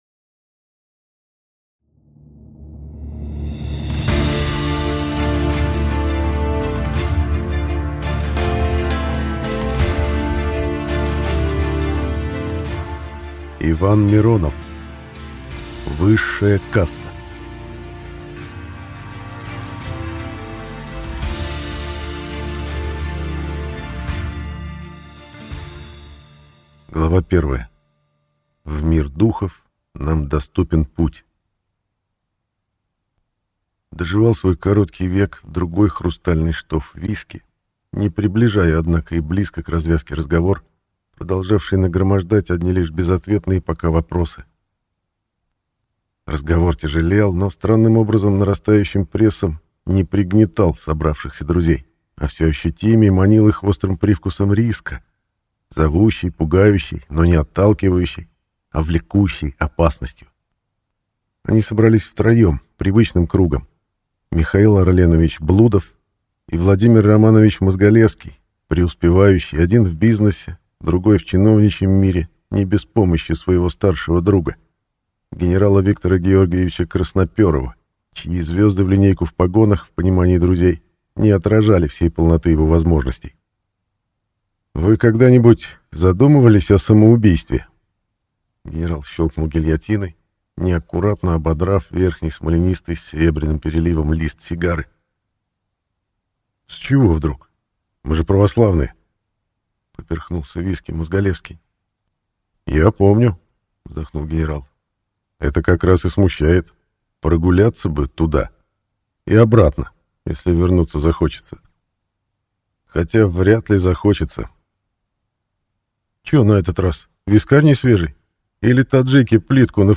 Аудиокнига Высшая каста | Библиотека аудиокниг